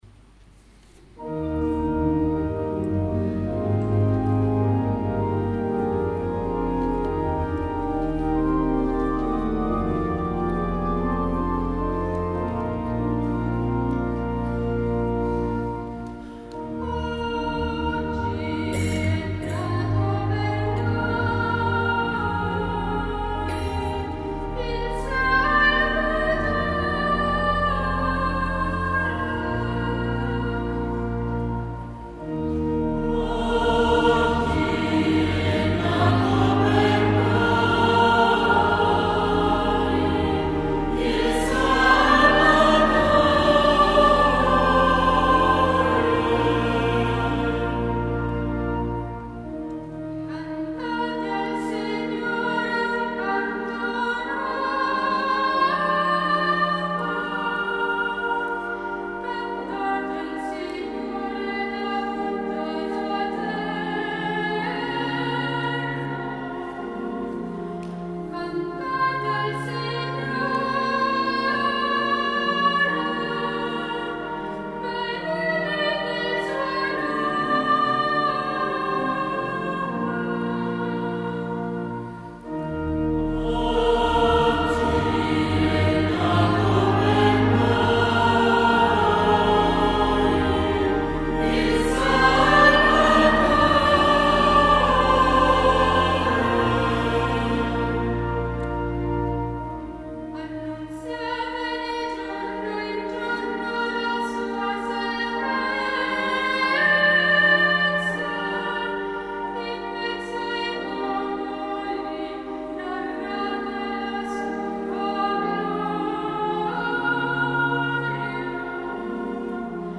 Multimedia : Audio/Audio2015/Notte di Natale/11-Nat15-SalmoResp - Duomo di Monreale
11-Nat15-SalmoResp.mp3